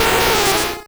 Cri de Nidoqueen dans Pokémon Rouge et Bleu.